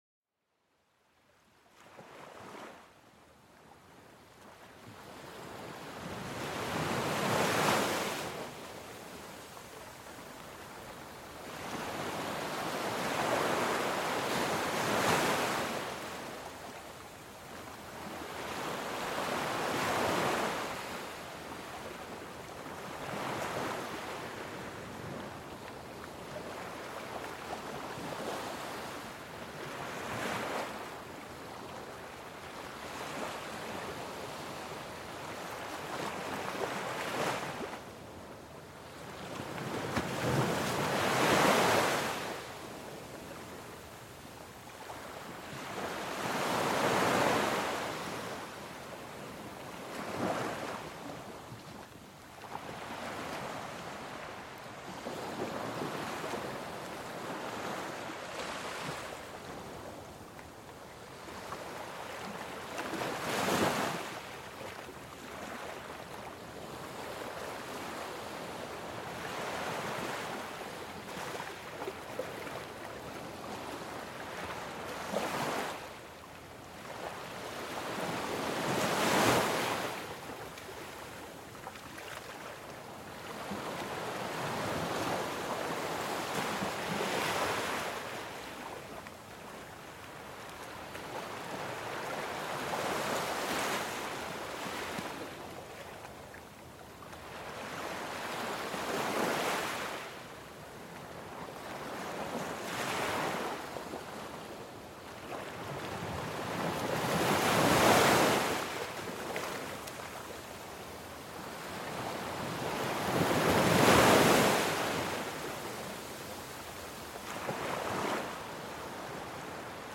Cet épisode vous invite à un voyage auditif au cœur de l'océan, où le doux murmure des vagues berce l'esprit et apaise l'âme. Découvrez comment le rythme répétitif des vagues contre le rivage peut réduire le stress et favoriser une profonde relaxation.